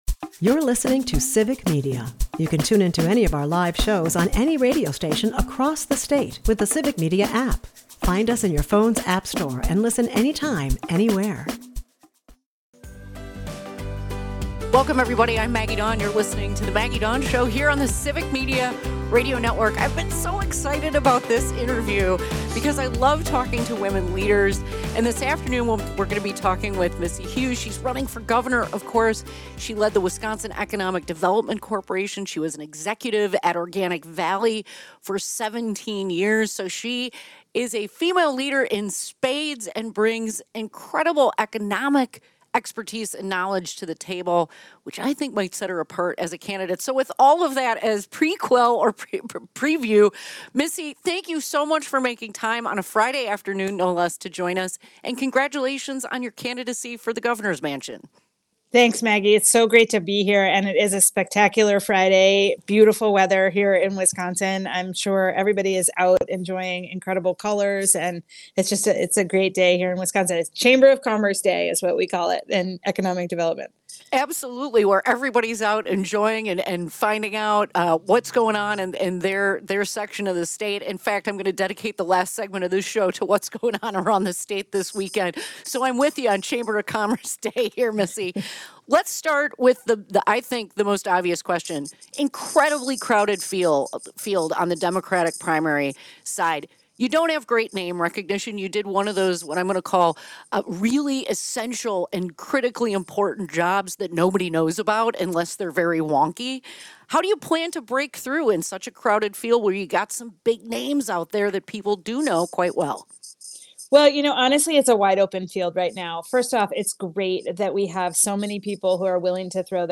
interviews gubernatorial candidate Missy Hughes